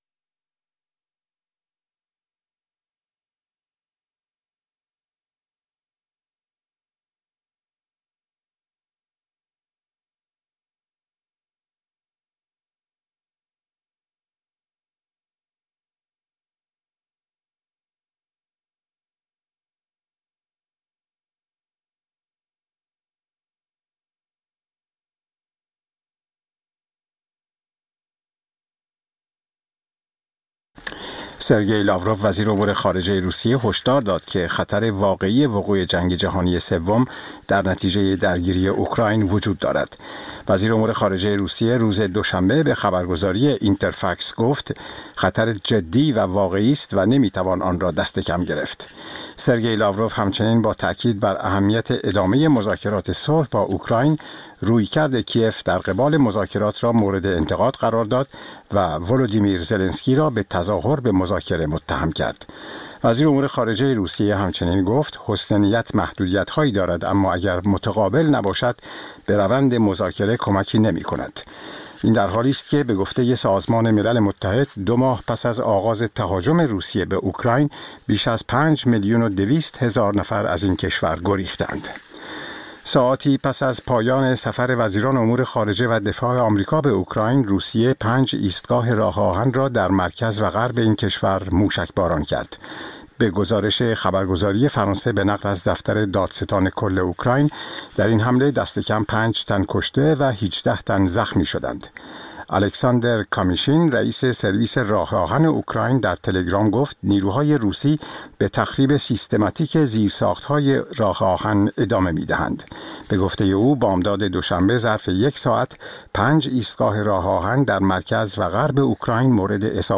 سرخط خبرها ۶:۰۰